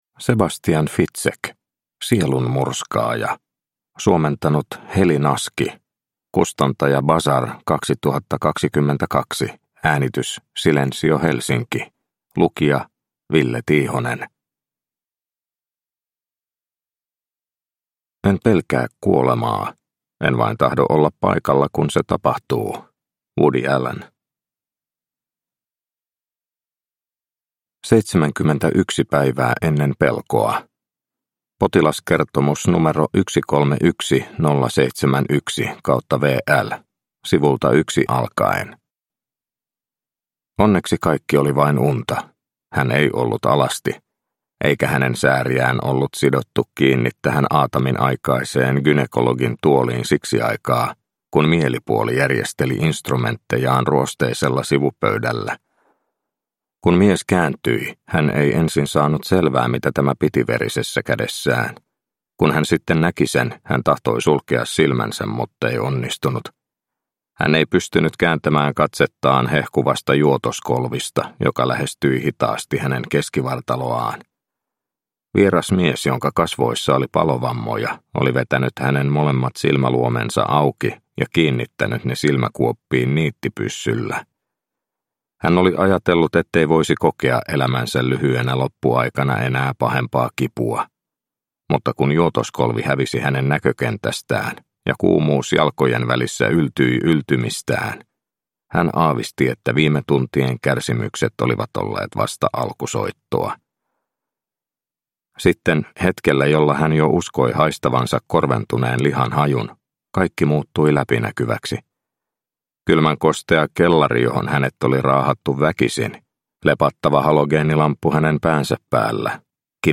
Sielunmurskaaja – Ljudbok – Laddas ner